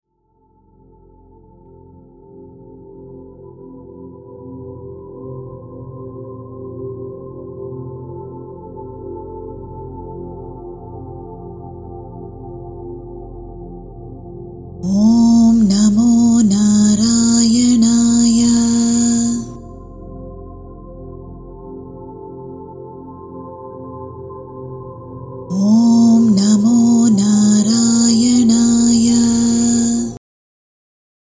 audio mantra meditation
108 sacred repetitions